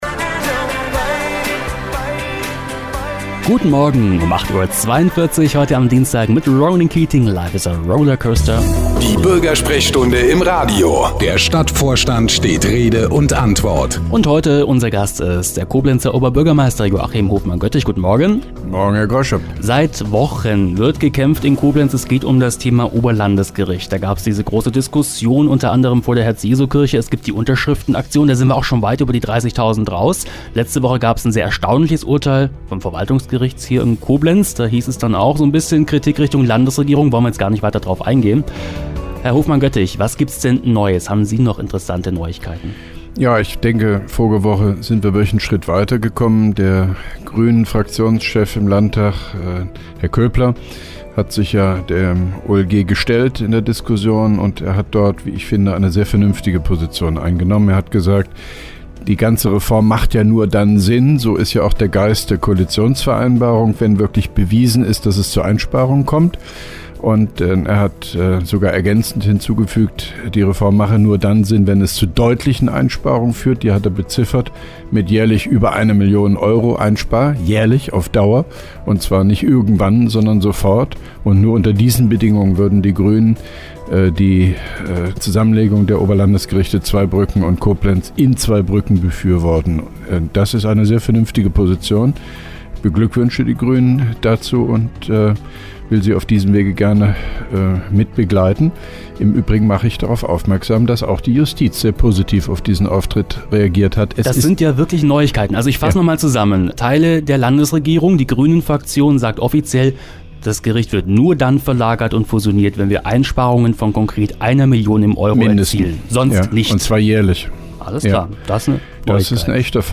(2) Koblenzer Radio-Bürgersprechstunde mit OB Hofmann-Göttig 02.08.2011